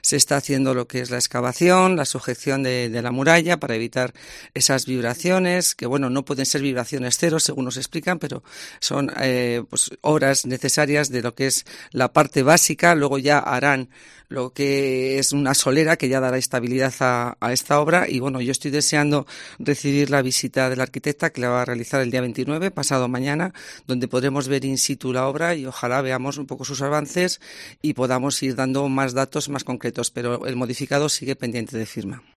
Marian Rueda, subdelegada del Gobierno en Segovia, sobre la rehabilitación del teatro Cervantes